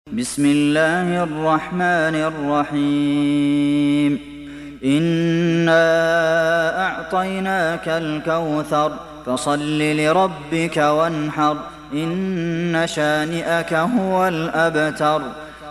تحميل سورة الكوثر mp3 بصوت عبد المحسن القاسم برواية حفص عن عاصم, تحميل استماع القرآن الكريم على الجوال mp3 كاملا بروابط مباشرة وسريعة